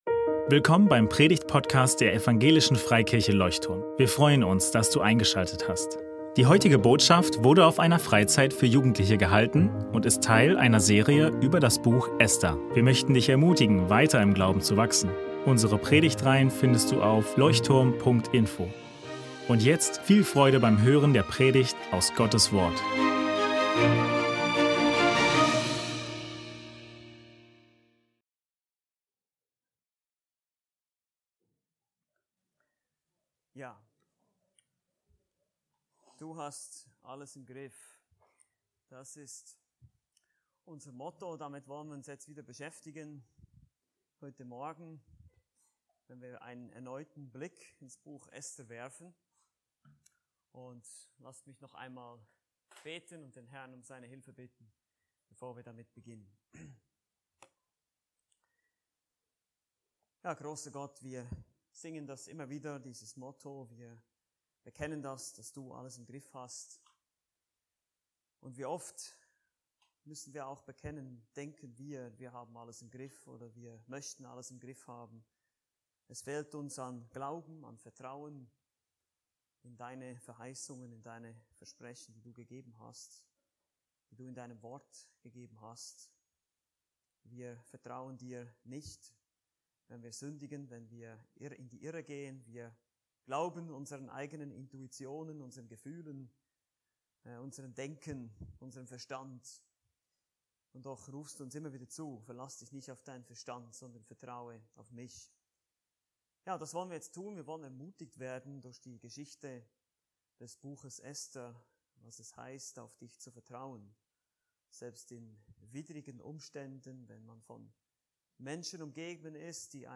Fieser Mordplan ~ Leuchtturm Predigtpodcast Podcast